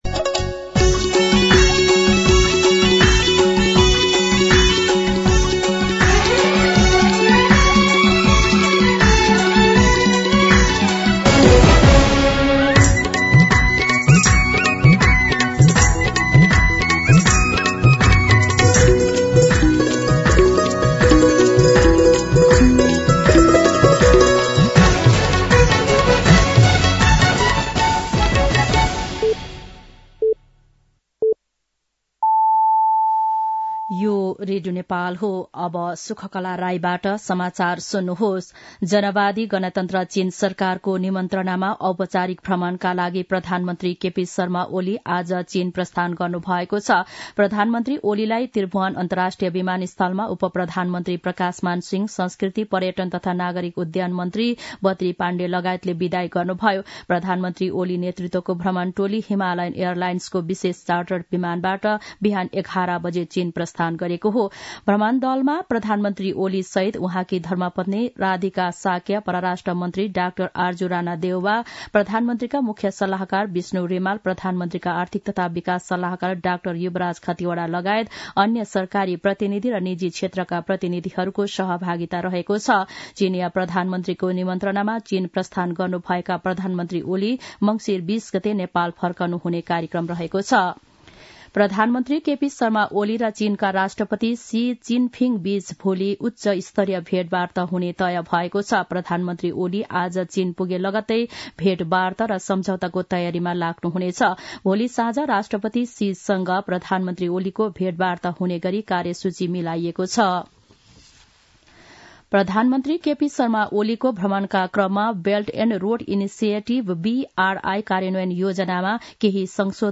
दिउँसो ४ बजेको नेपाली समाचार : १८ मंसिर , २०८१
4-pm-news-.mp3